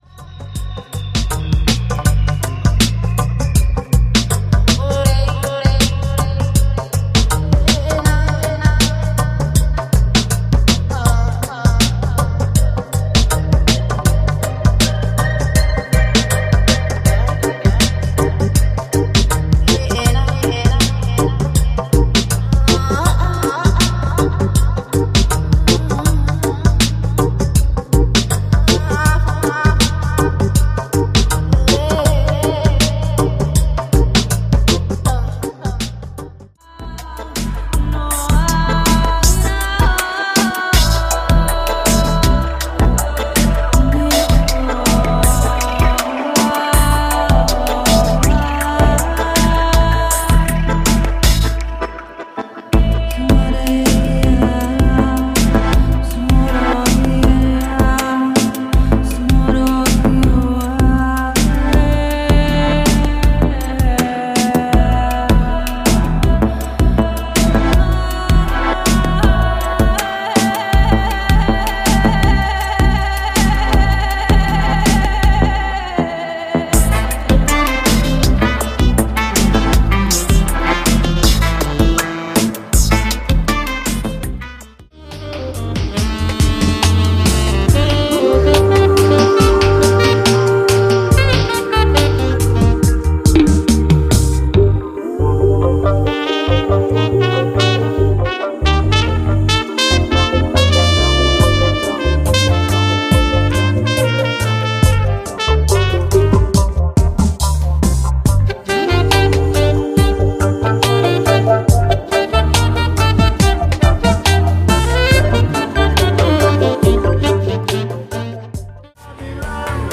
Стиль: Downtempo, IDM